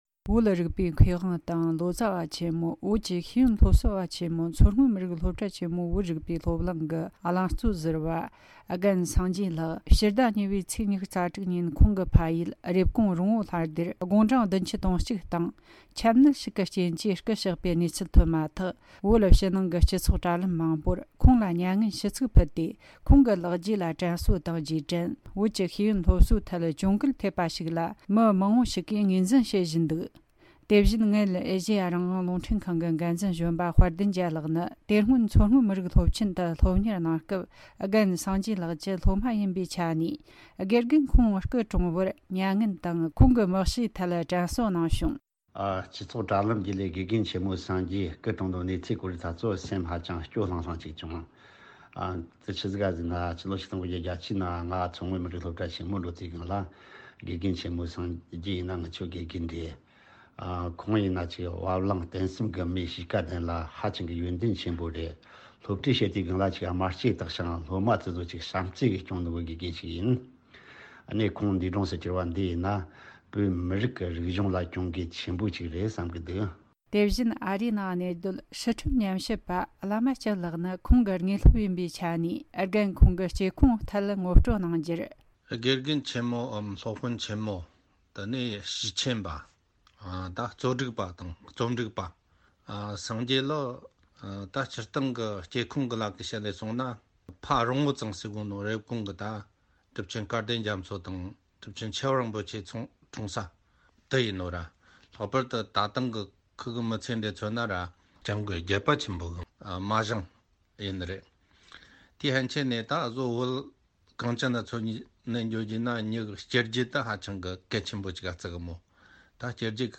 བོད་ནང་གི་འབྲེལ་ཡོད་བོད་མི་ཞིག་ནས་འགྲེལ་བརྗོད་གནང་བྱུང་།